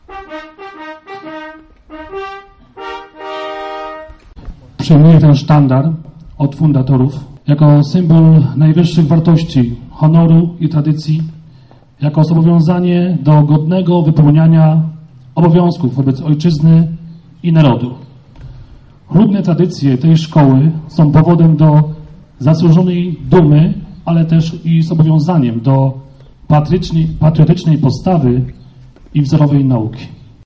W miniony piątek w Dąbrowie odbyła się uroczystość nadania imienia i sztandaru gimnazjum przy Zespole Szkół. Tym samym od kilku dni placówka nosi imię założyciela Unii Europejskiej Roberta Szumana.